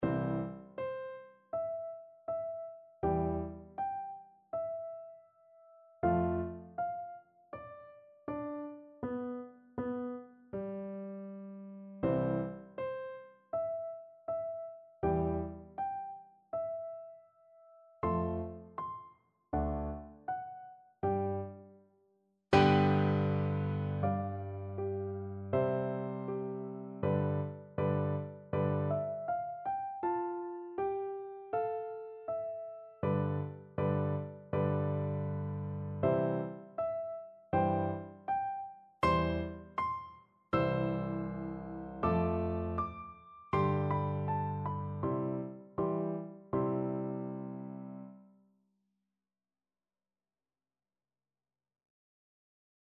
Free Sheet music for Piano Four Hands (Piano Duet)
Andante
Classical (View more Classical Piano Duet Music)